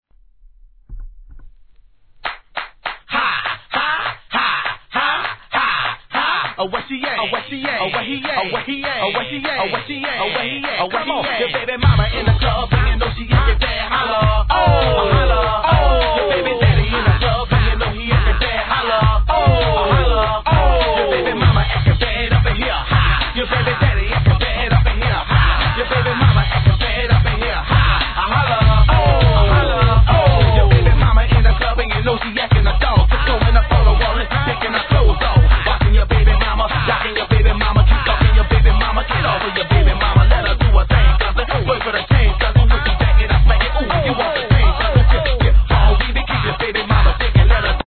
G-RAP/WEST COAST/SOUTH
テンションガチ上げのトラックに思わず体が揺れること間違いなし!!